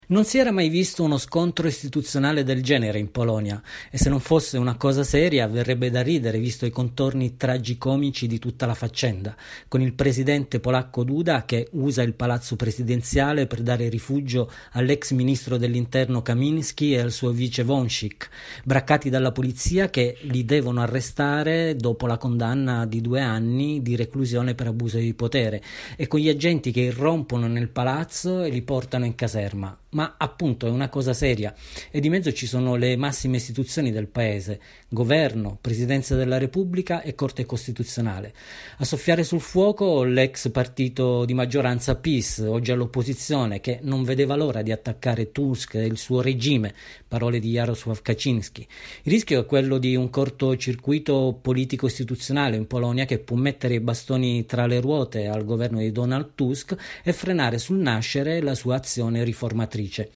Da Varsavia